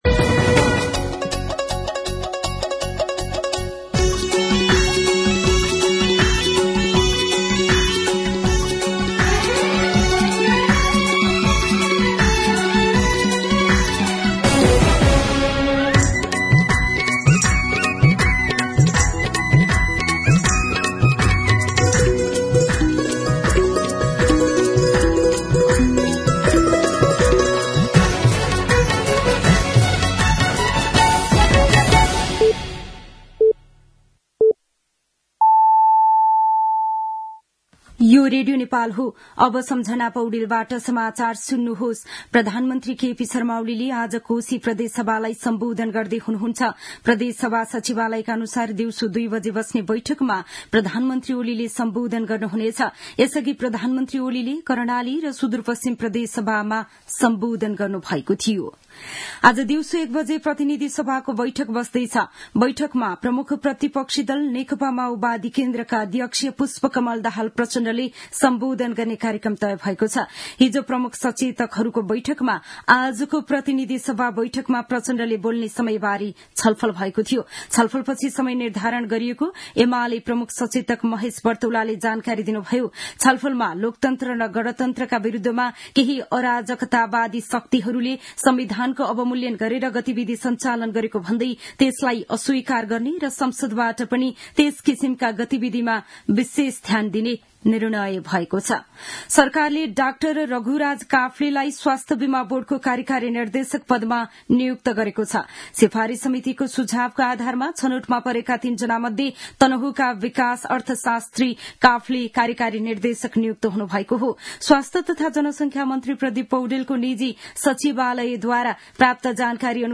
मध्यान्ह १२ बजेको नेपाली समाचार : २८ फागुन , २०८१